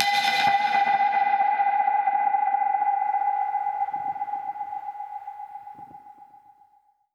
Index of /musicradar/dub-percussion-samples/134bpm
DPFX_PercHit_A_134-01.wav